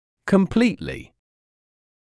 [kəm’pliːtlɪ][кэм’плиːтли]полностью, целиком, всецело